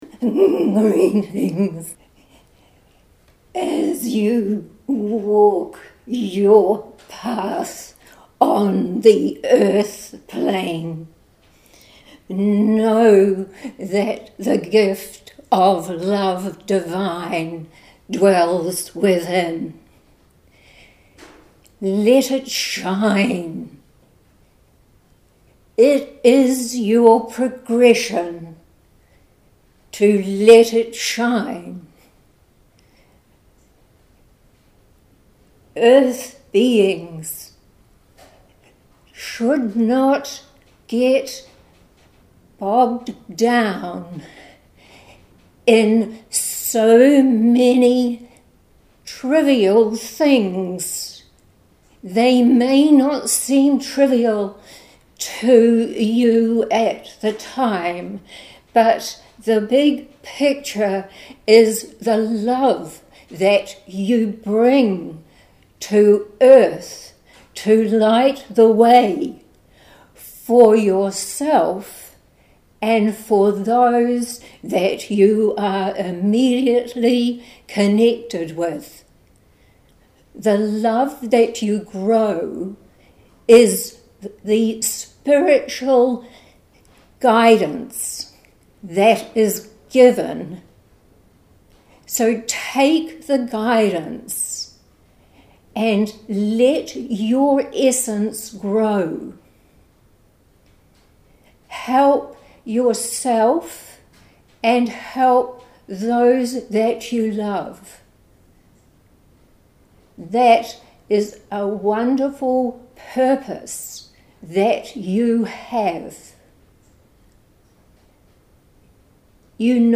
“Walk Proudly With The Light” – audio recording of channelled message from The Circle of The Light of The Love Energy
Posted in Audio recording, Channelled messages, Metaphysical, Spirituality, Trance medium